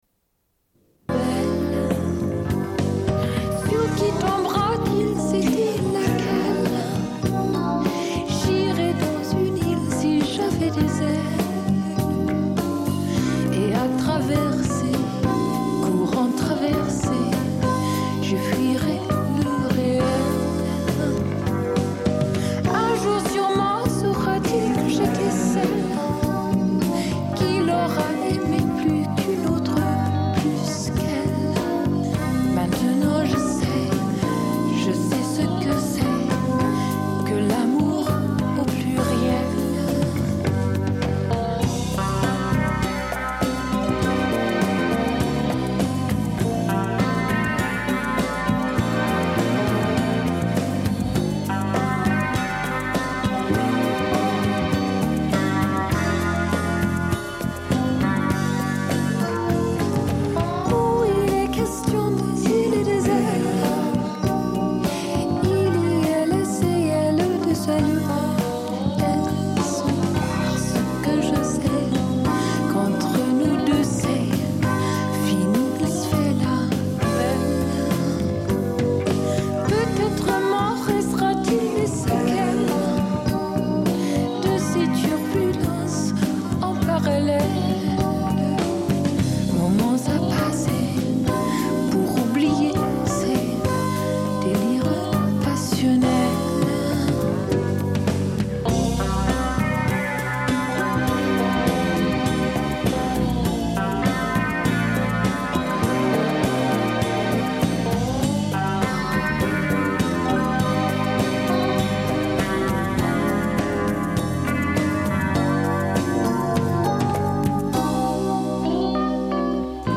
Une cassette audio, face B29:29